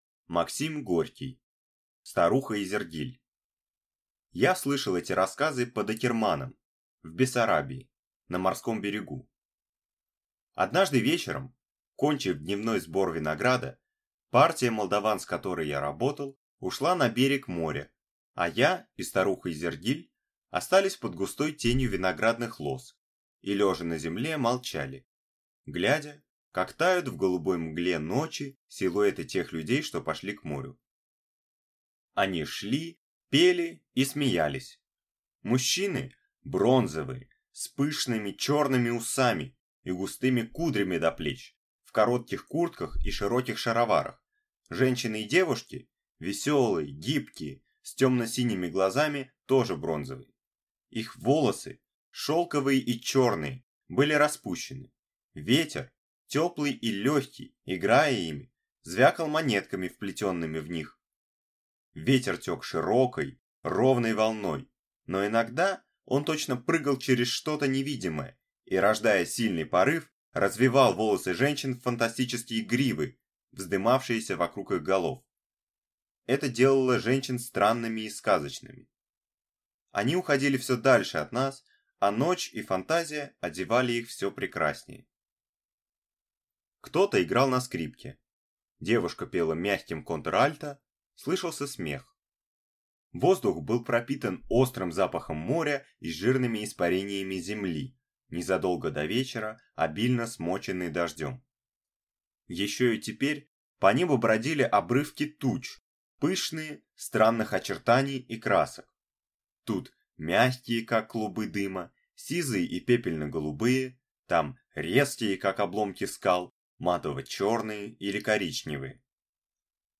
Аудиокнига Старуха Изергиль | Библиотека аудиокниг